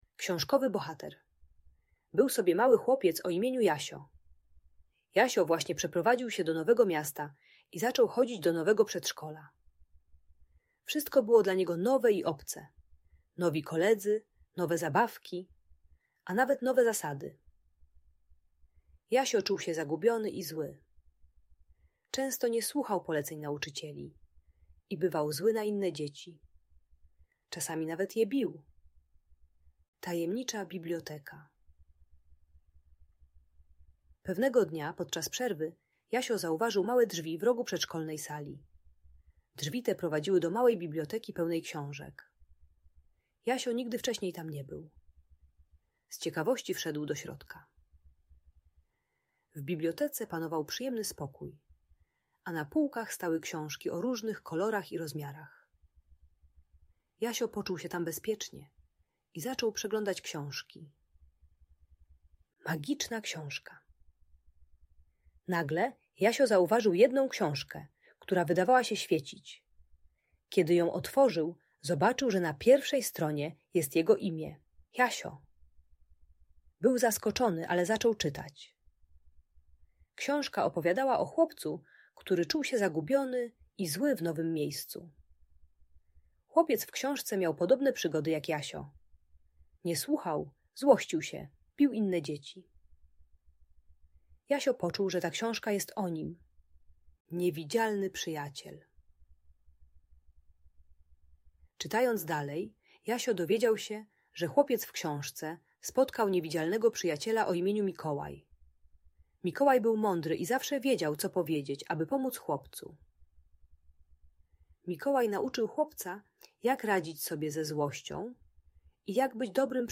Magiczna historia Jasia: Opowieść o przyjaźni i emocjach - Audiobajka